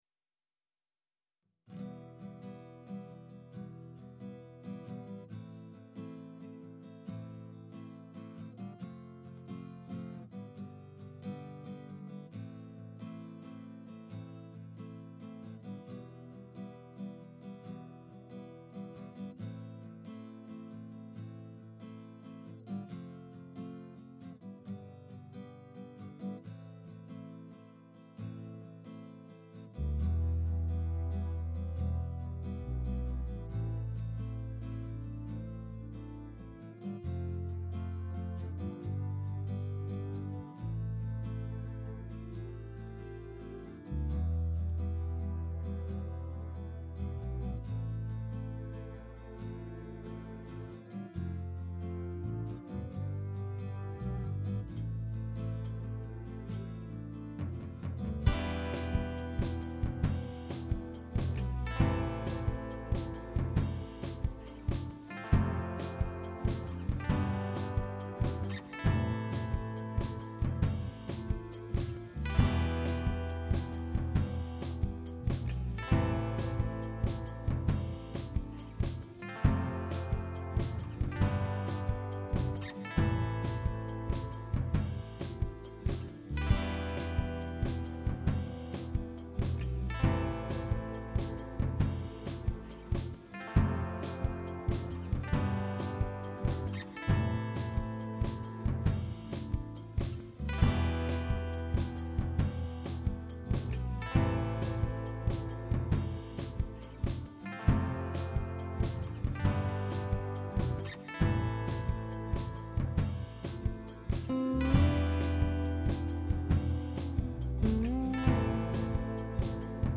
Here's a mix of a backing track I've been working on this week, that I've tried to master myself (I've not really got a budget for a mastering house) using cubase native plugins. There's no vocals at the moment as I've not written lyrics or melody yet - I'm just really looking for advice/comments on the mix of the backing track - it's only about 3mins so if you can manage to listen all the way through that'd be great!